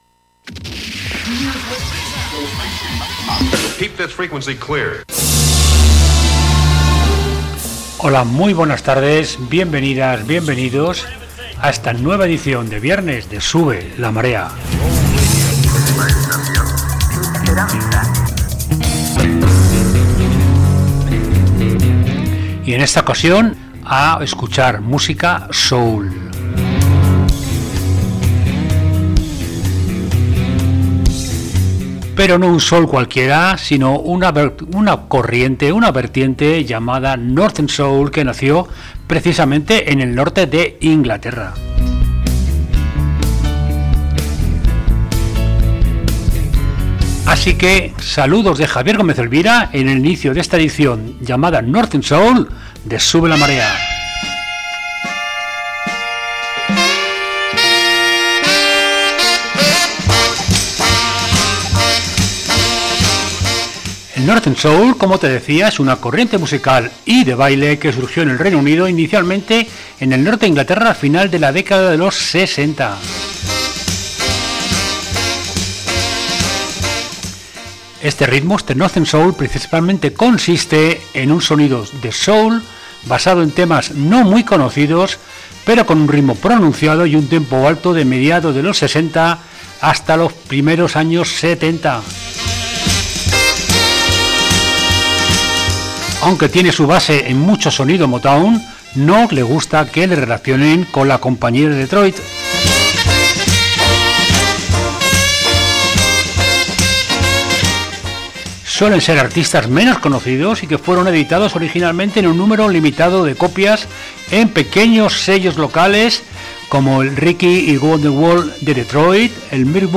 Sube la Marea: Northern Soul - 2.2 Radio